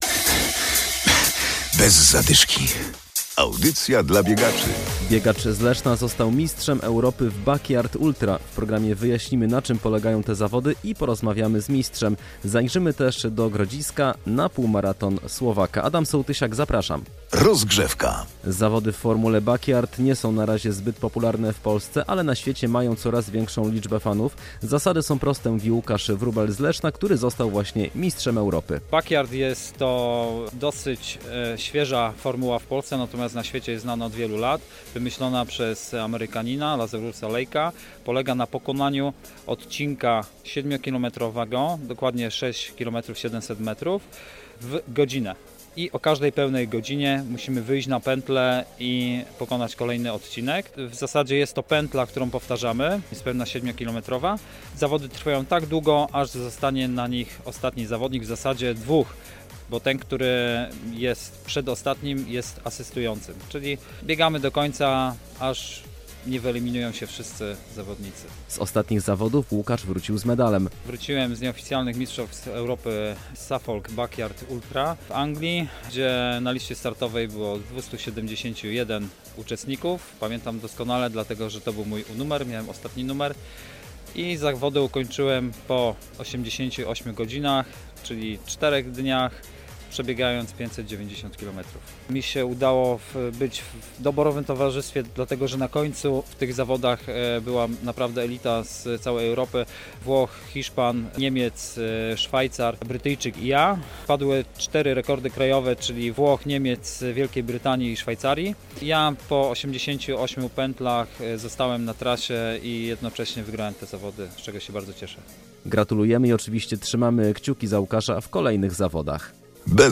Relacja z półmaratonu w Grodzisku oraz rozmowa